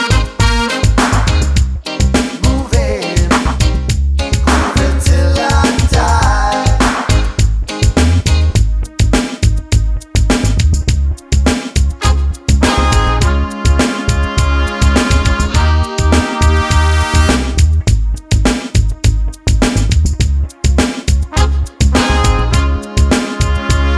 No Saxophone Solo Reggae 3:31 Buy £1.50